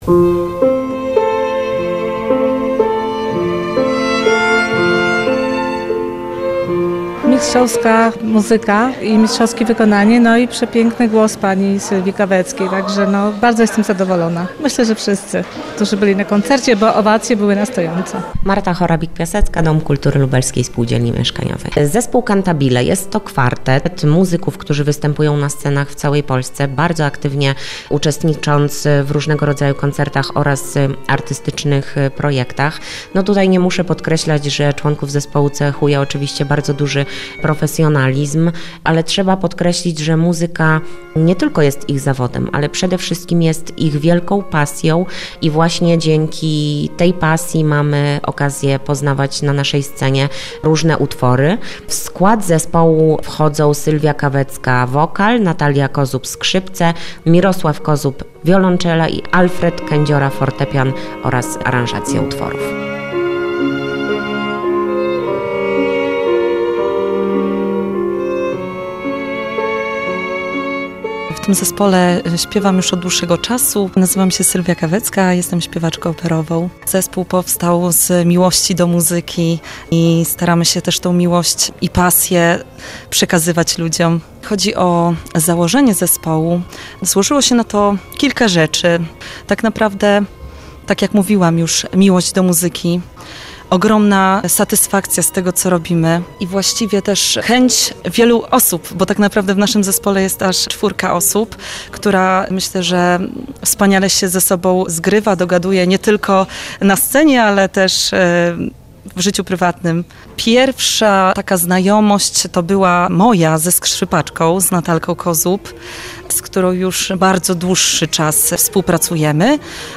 W Domu Kultury LSM odbył się koncert szlagierów muzyki filmowej w wykonaniu zespołu Cantabile. Jest to kwartet muzyków, którzy występują na scenach w całej Polsce, aktywnie uczestnicząc w różnego rodzaju koncertach oraz artystycznych projektach.